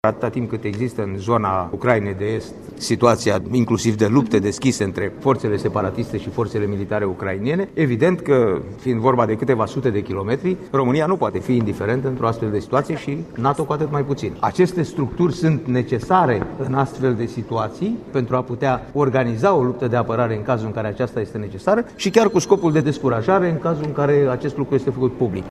Secretarul Biroului permanent al Camerei Deputaţilor, Nicolae Mircovici, a precizat că este vorba de o unitate de integrare a forţelor NATO şi un comandament multinaţional de divizie, structuri care vor activa în Ganizoana Bucureşti.